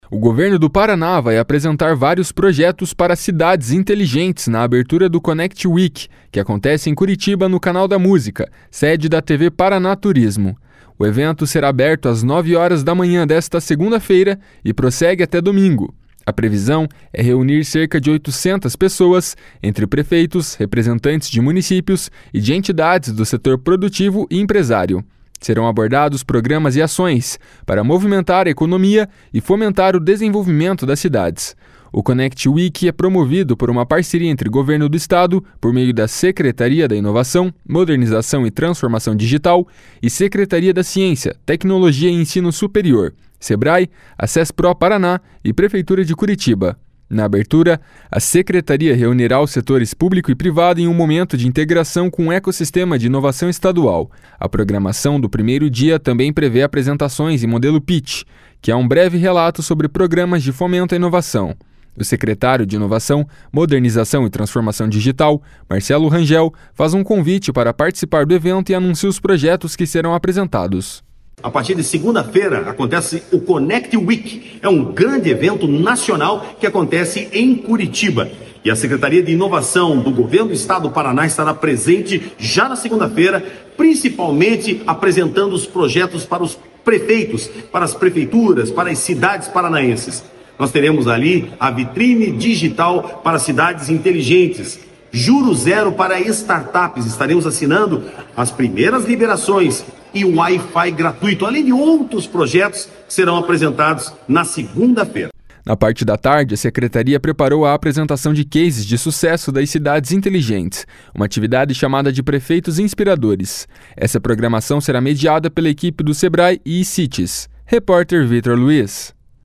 O secretário de Inovação, Modernização e Transformação Digital, Marcelo Rangel, faz um convite para participar do evento e anuncia os projetos que serão apresentados.